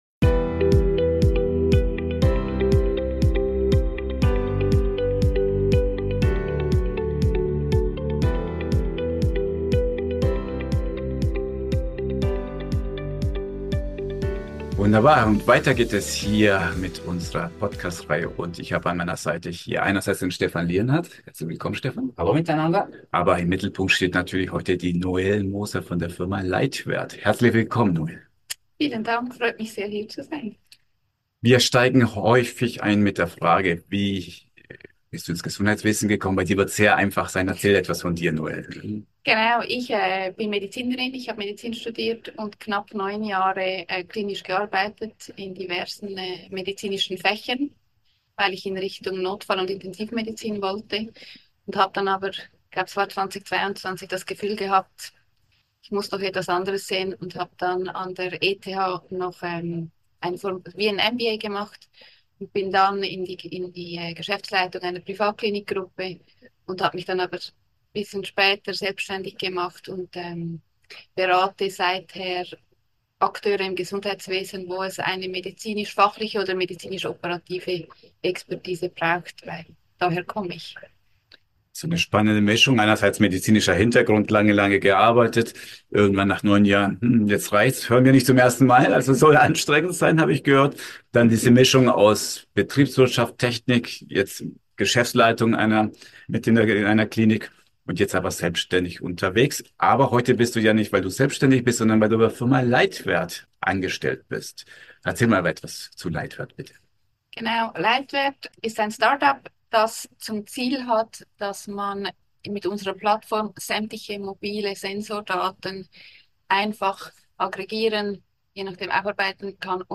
Kompakt Interview 04: Diese Startups machen Kliniken smarter und die Haut von Kinder gesünder! ~ Rocketing Healthcare: Startups & Innovationen im Gesundheitswesen Podcast